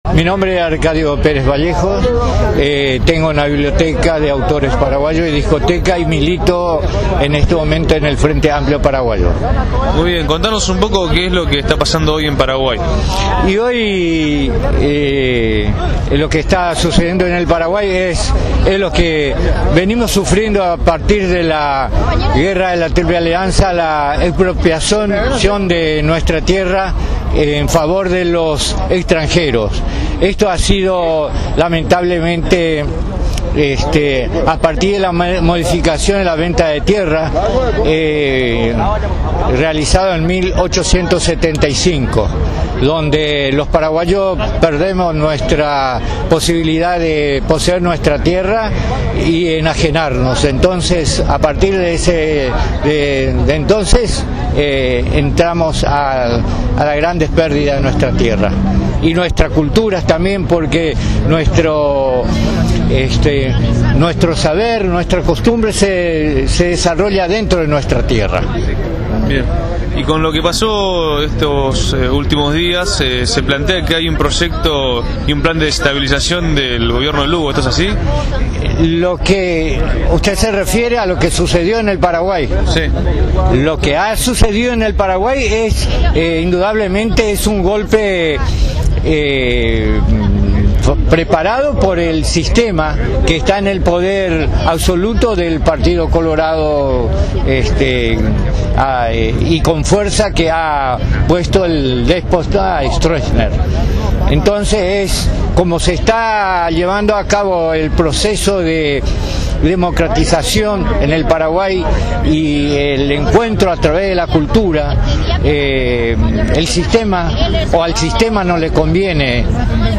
Convocados por el Congreso Permanente de Migrante Paraguayos de la Argentina, hoy a las 18 horasmás de 50 compatriotas se reunieron en el Obelisco para denunciar un plan desetabilizador del Gobierno de Fernando Lugo.